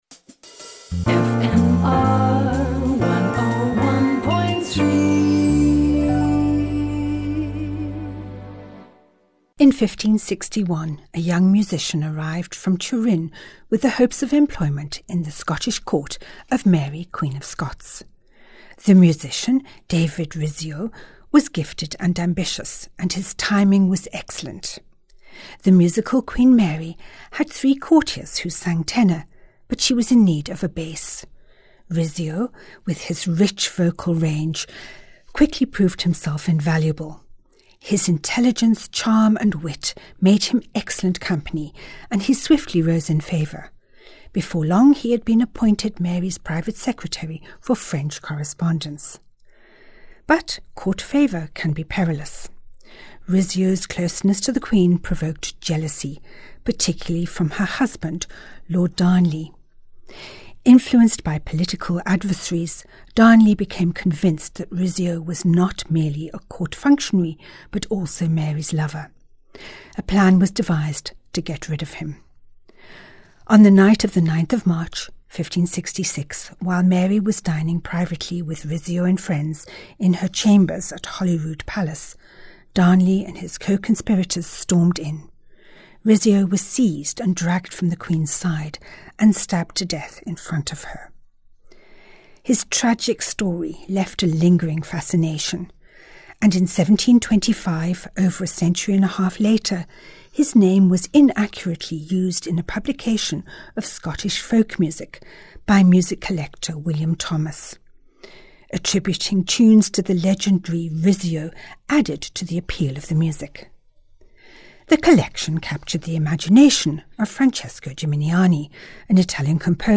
From the esteemed Handel having his life saved by his jacket button while duelling to the latest discoveries of Baroque scores in dusty attics. Each weekly Bon Bon is accompanied by a piece of Baroque music which ties in with the story.